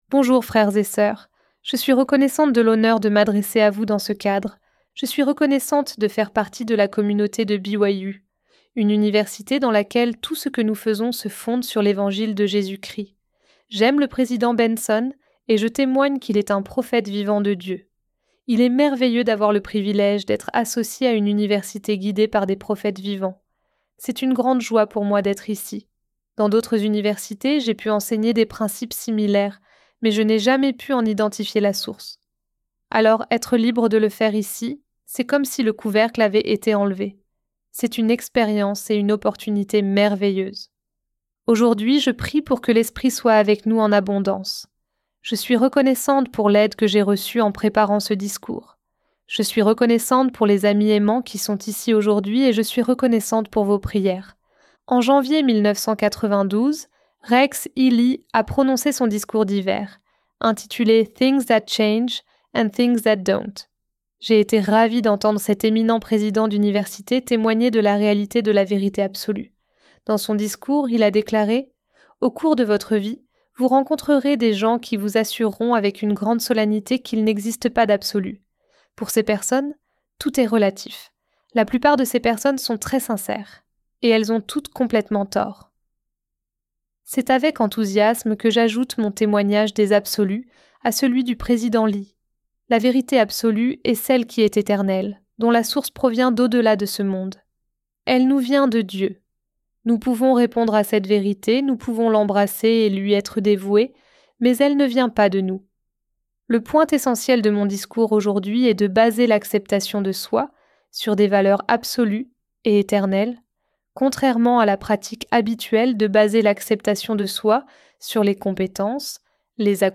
Réunion spirituelle